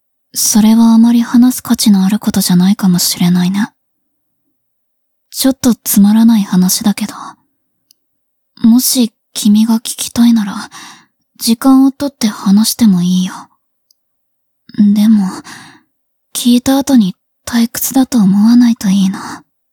文件 文件历史 文件用途 全域文件用途 闲聊-过去的自己.wav （WAV音频文件，总共长20秒，码率1.54 Mbps，文件大小：3.61 MB） 摘要 灵魂潮汐：克拉丽丝语音 许可协议 本作品仅以介绍为目的在此百科中以非盈利性方式使用，其著作权由原著作权人保留。